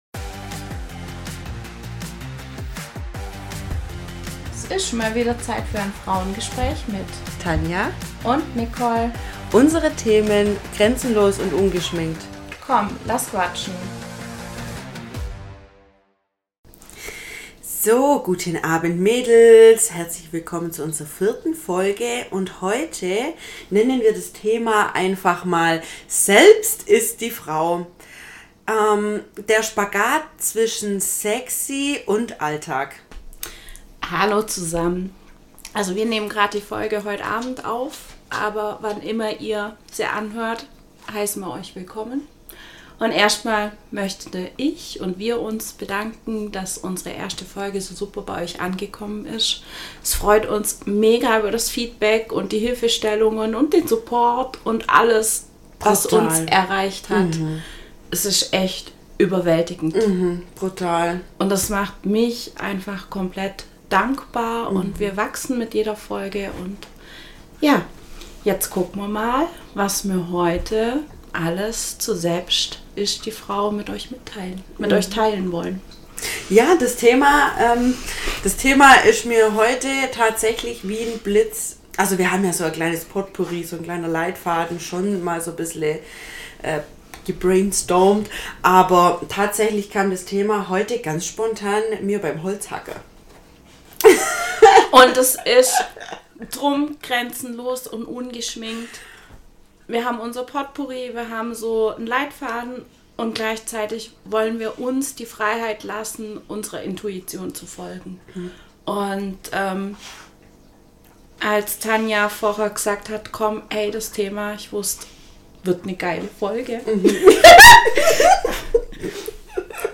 Tadaaa, ein weiteres Frauengespräch ist für Euch entstanden...